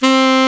SAX A.MF C06.wav